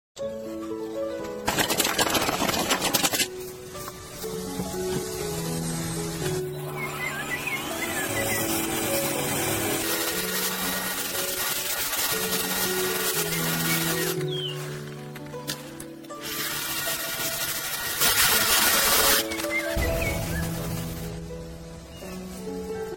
Listen to the most primitive sound effects free download
Listen to the most primitive sound from bamboo to cups